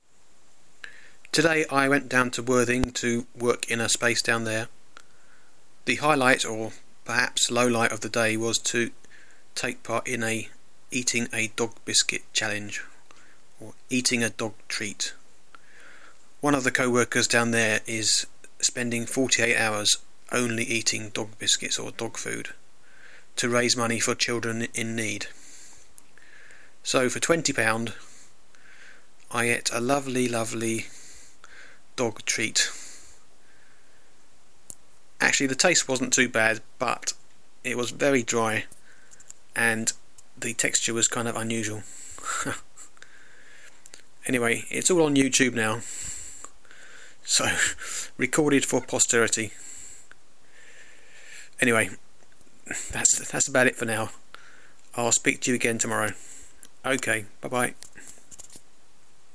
Dog treat eating